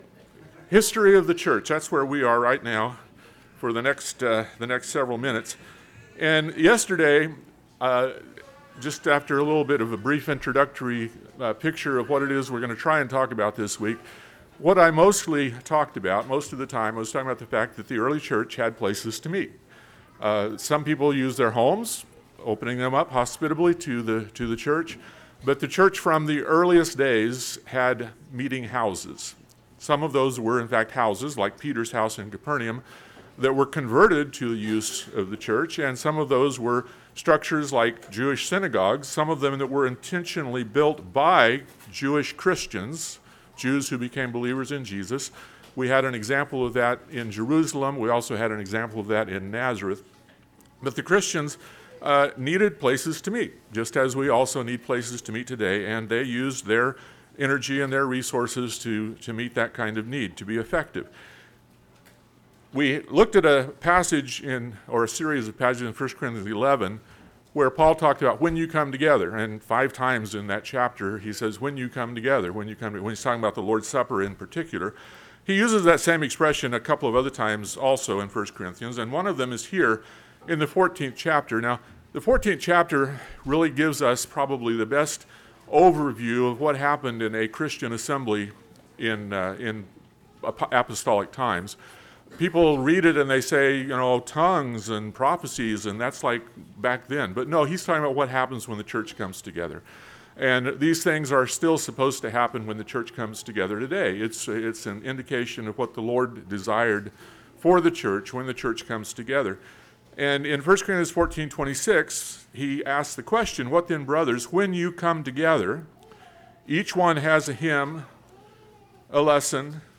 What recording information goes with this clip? This podcast is a collection of Gospel speeches given by members of the Lord's Body at worship services and meetings.